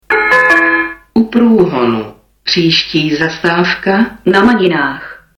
- Hlášení zastávky "U Průhonu. Příští zastávka Na Maninách" si